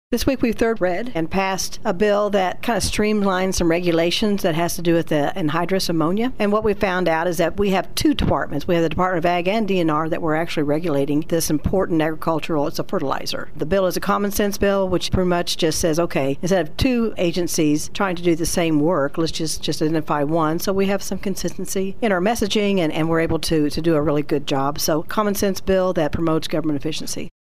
State Senator Karla Eslinger talked about a couple of bills this week in her weekly update. Senate Bill 37 is legislation that would modify provisions relating to anhydrous ammonia